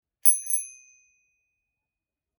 Разные велосипедные звуки: колеса и трещотки велосипедные, езда на велосипеде, звонок, тормоза, цепи.
8. Велосипедный звонок на смс
velozvon-1s.mp3